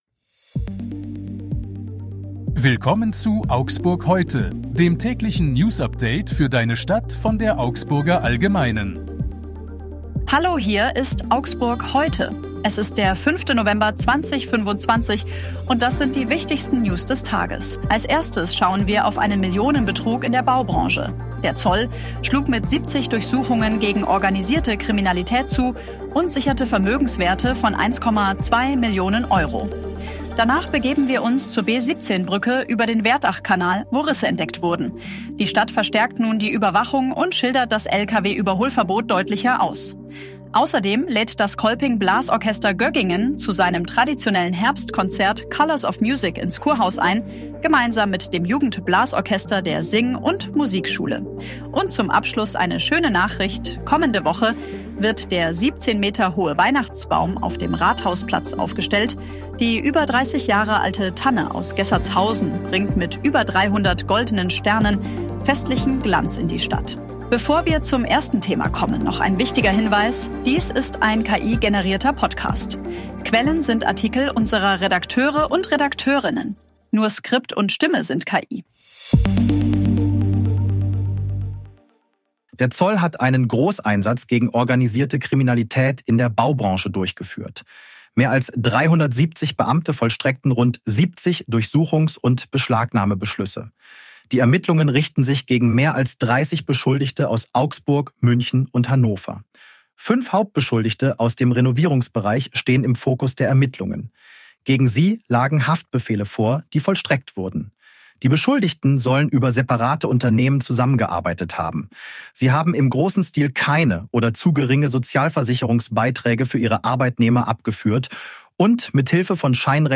Stimme sind KI.